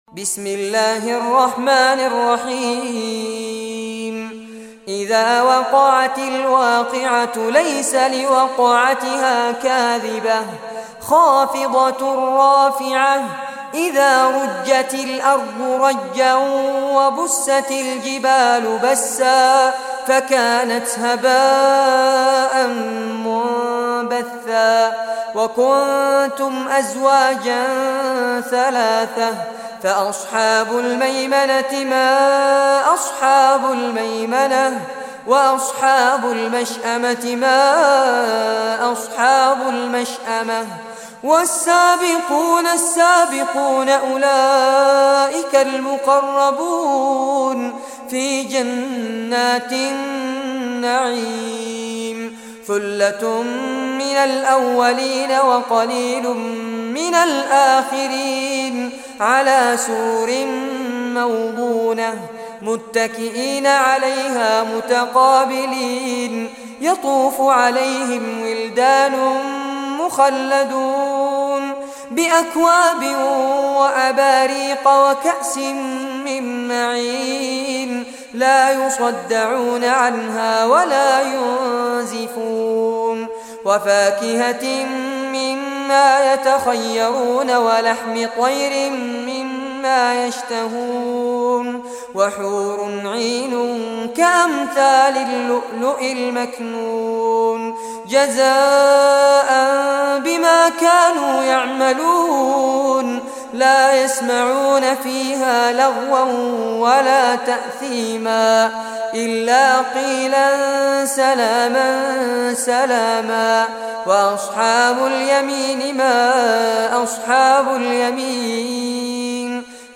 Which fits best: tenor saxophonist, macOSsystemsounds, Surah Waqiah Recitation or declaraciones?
Surah Waqiah Recitation